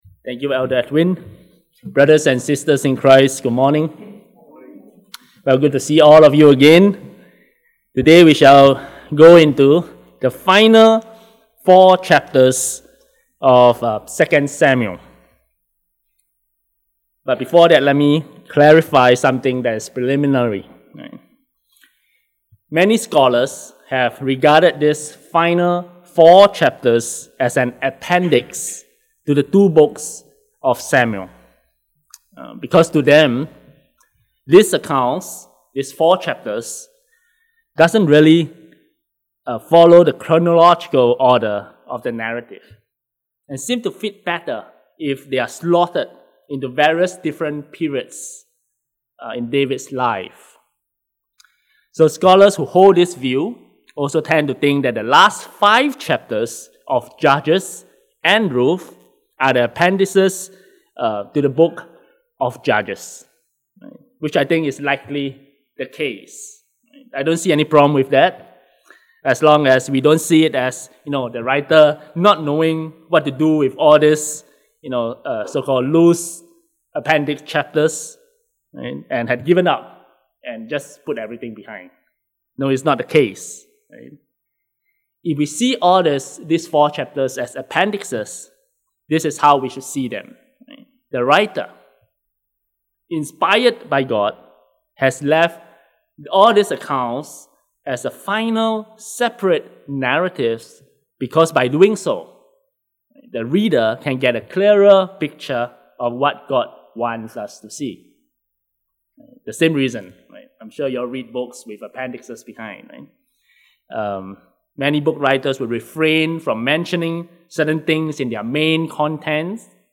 Scripture: 2 Samuel 21 To download the sermon